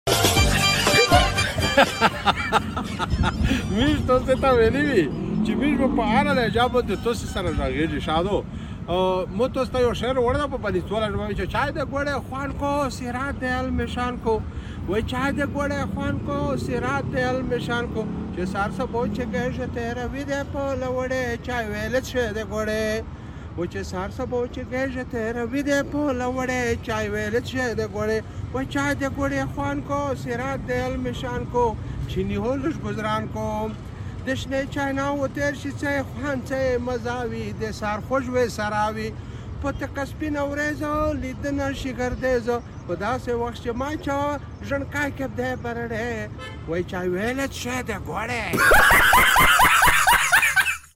د بنو په خوږه لهجه لنډ خو خوندور ترنم واورئ!